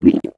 drink_honey2.ogg